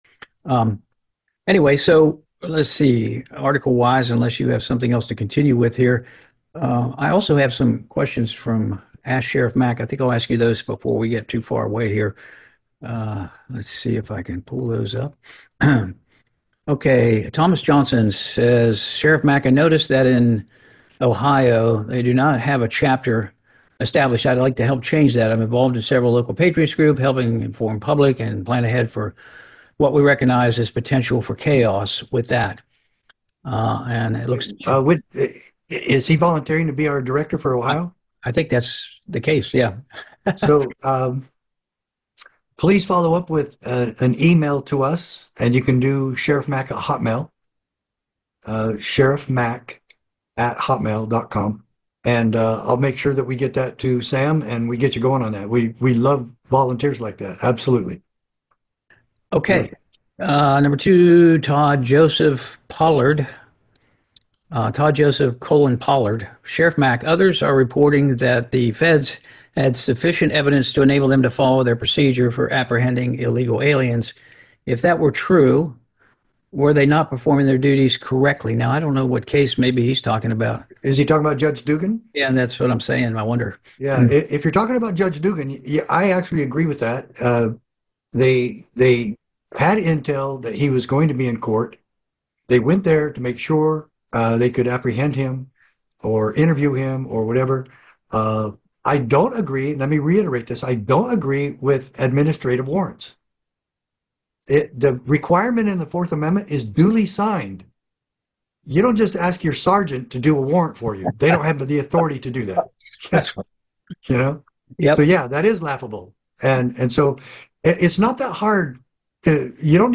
➡ The text discusses a conversation where Sheriff Mack answers questions from the public.